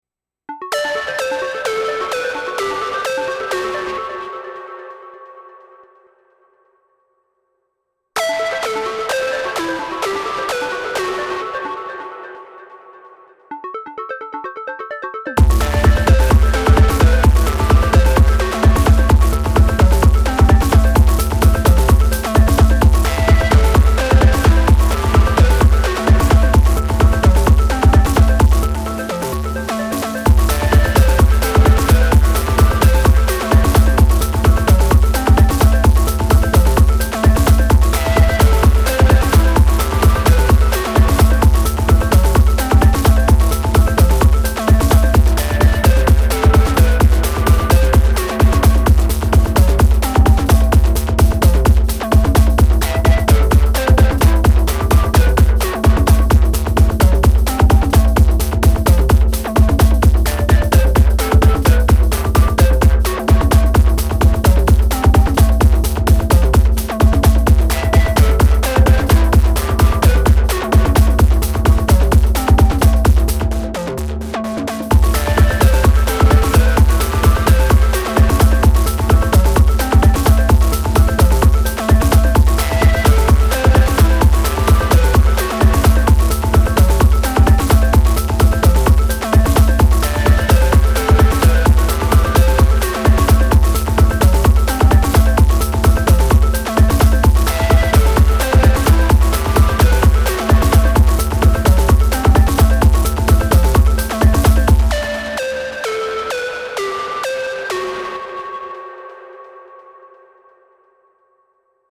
So I ran half the tracks through the FX, maxed out the drive, increased the tempo a lot, and added noise on all tracks :sweat_smile:
No real science here, just playing around with melodic sequences and drums.